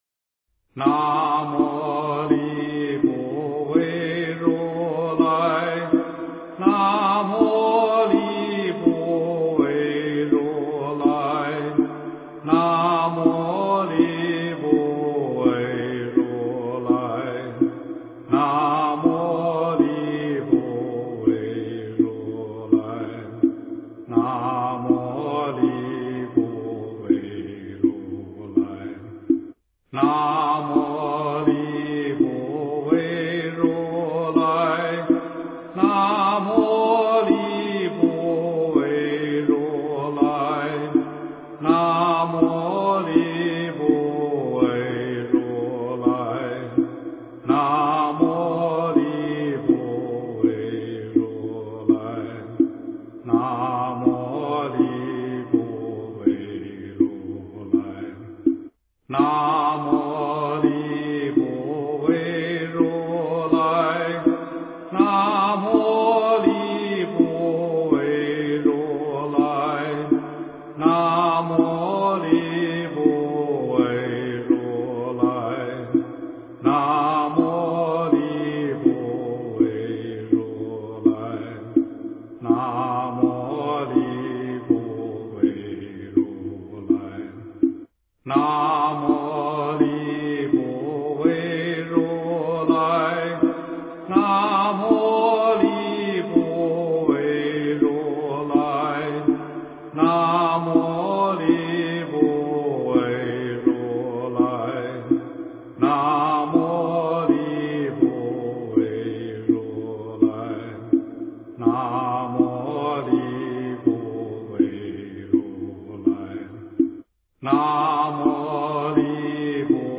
经忏
佛教音乐